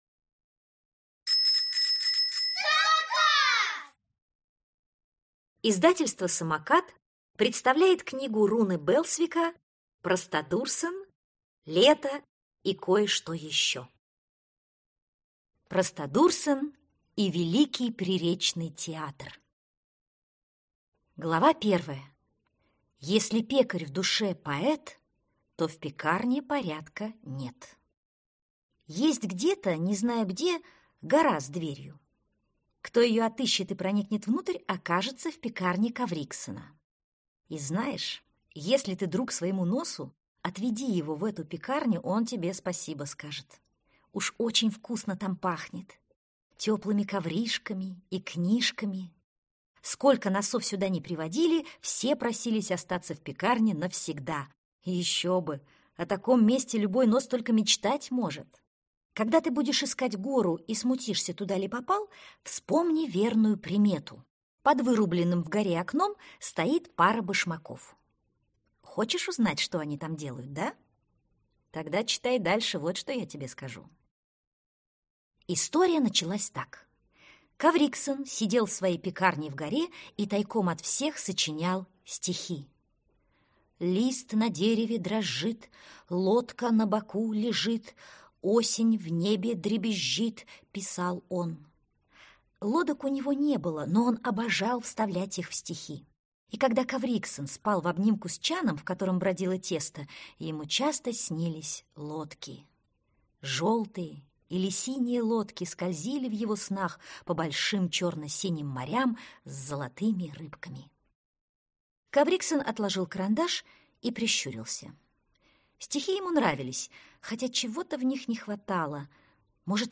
Аудиокнига Простодурсен Лето и кое-что еще | Библиотека аудиокниг